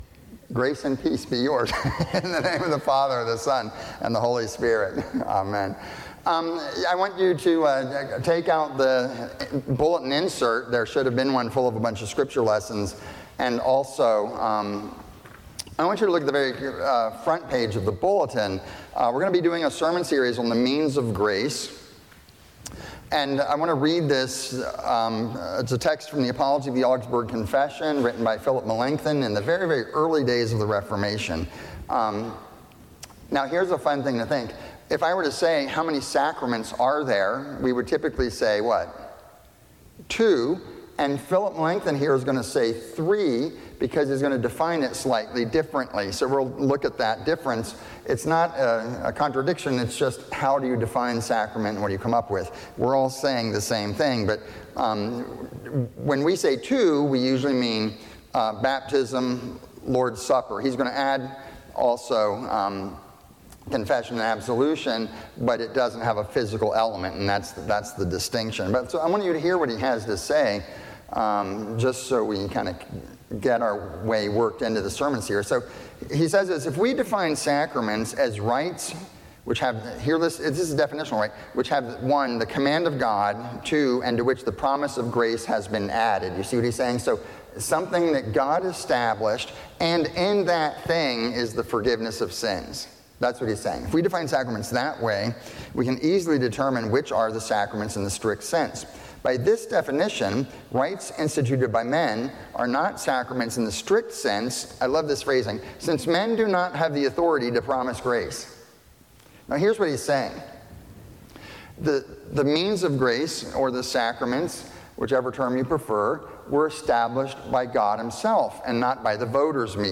You may view the sermon on our YouTube channel.